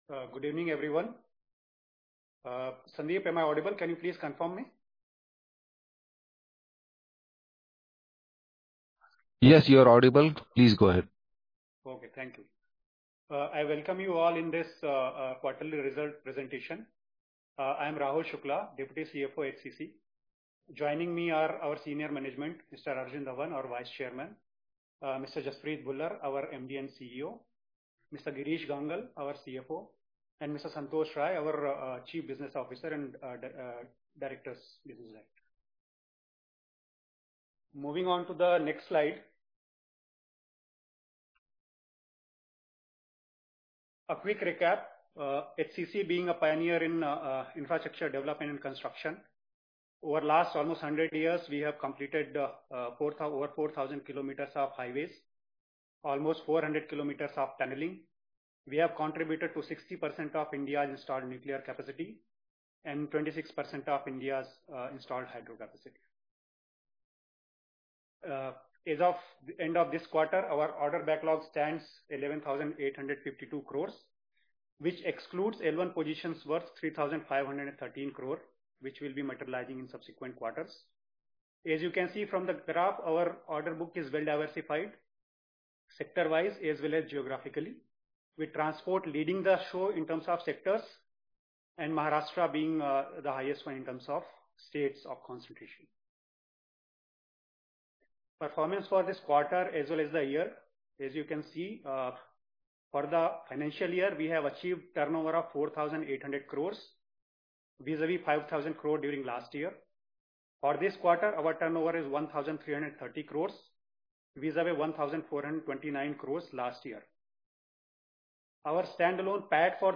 Investor Call Recordings
Analyst_meet_recording_Q4_FY25.mp3